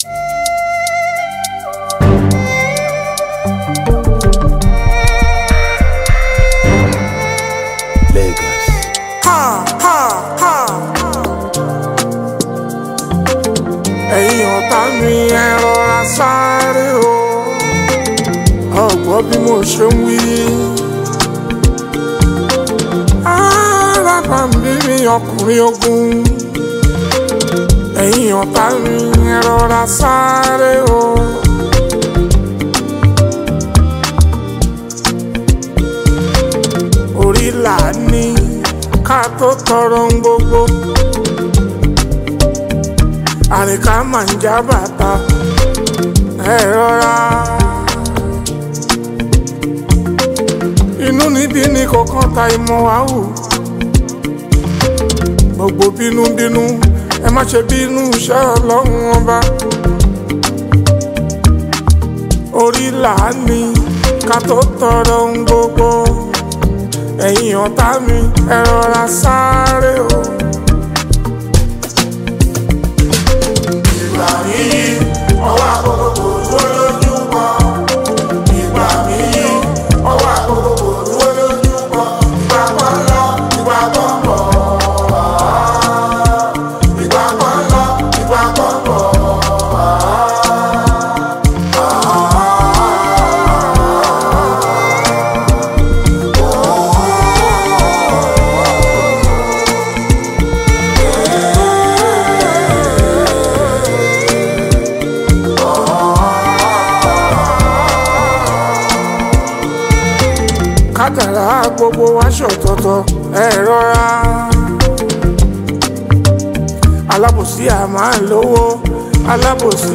Fuji, Highlife
Nigerian Yoruba Fuji track